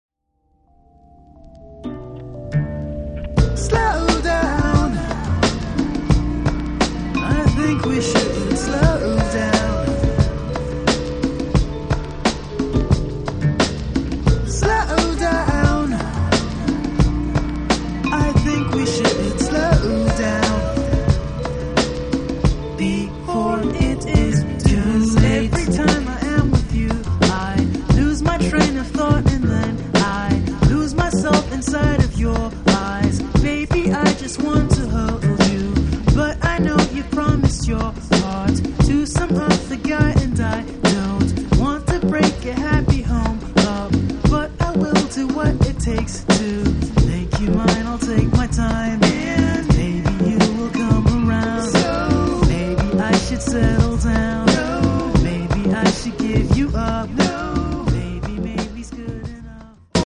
Afro-Soul Hip-hop rockin!